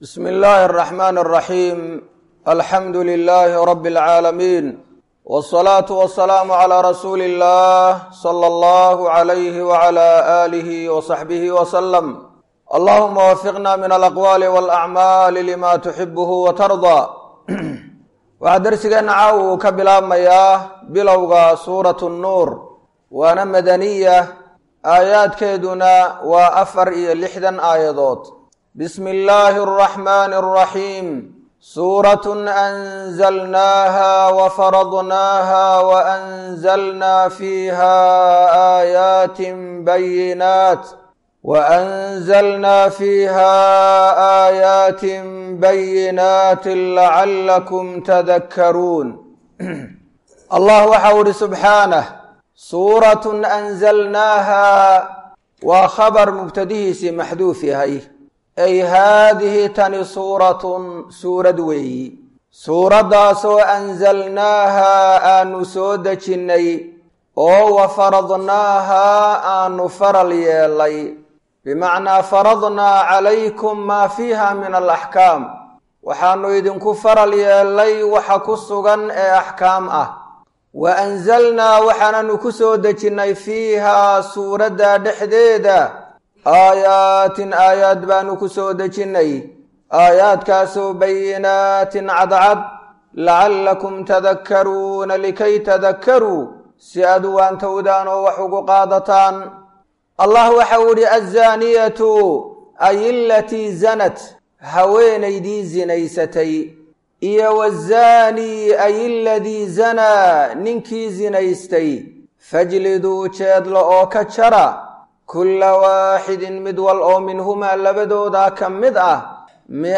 Waa Tafsiirka Qur’aanka Ee Ka Socda Masjid Ar-Rashiid – Hargaisa